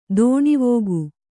♪ dōṇivōgu